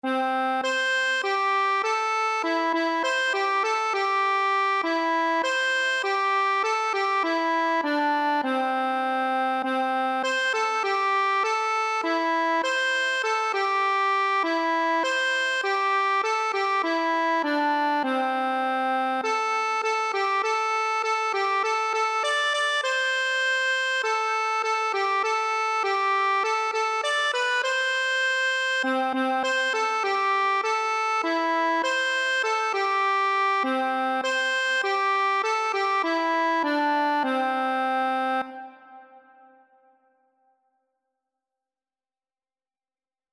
Traditional Trad. Get That Boat Accordion version
4/4 (View more 4/4 Music)
C5-D6
C major (Sounding Pitch) (View more C major Music for Accordion )
Accordion  (View more Easy Accordion Music)
Traditional (View more Traditional Accordion Music)